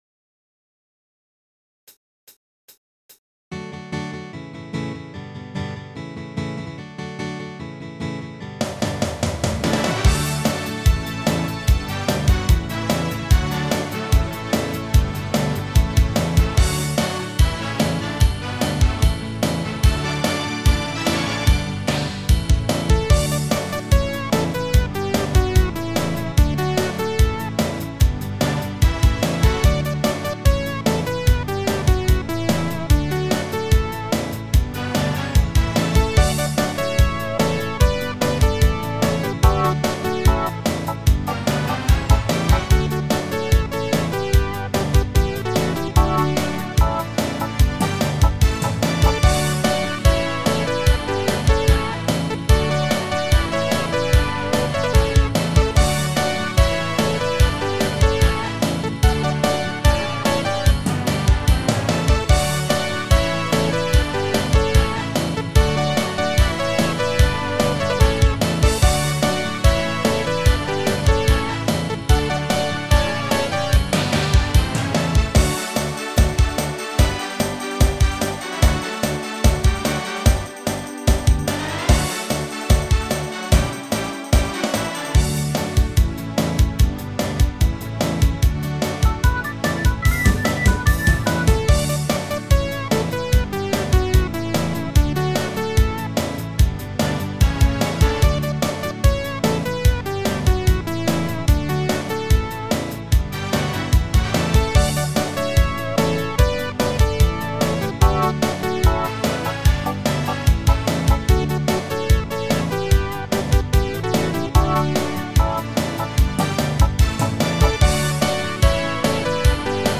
PRO MIDI Karaoke INSTRUMENTAL VERSION
Alpenrock